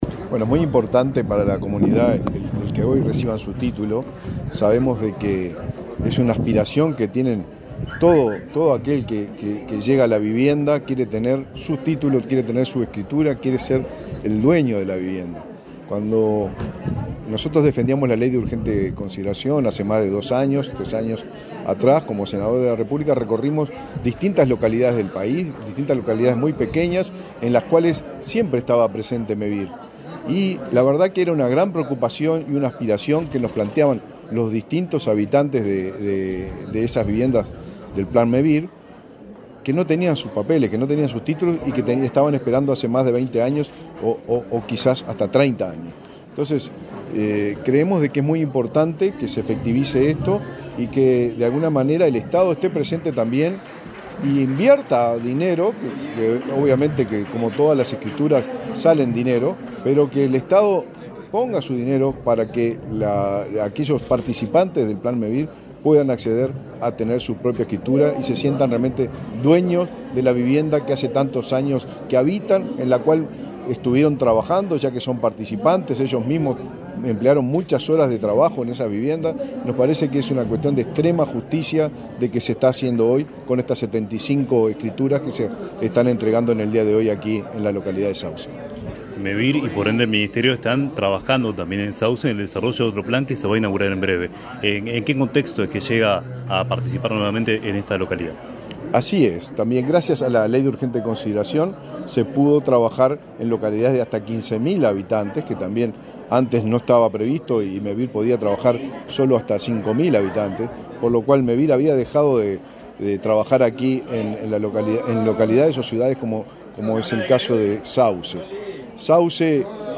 Declaraciones del ministro de Vivienda, Raúl Lozano
Declaraciones del ministro de Vivienda, Raúl Lozano 25/09/2023 Compartir Facebook X Copiar enlace WhatsApp LinkedIn Este lunes 25, el presidente de Mevir, Juan Pablo Delgado, y el ministro de Vivienda, Raúl Lozano, participaron en un acto de escrituras colectivas en la localidad de Sauce, departamento de Canelones. Luego Lozano dialogo con Comunicación Presidencial.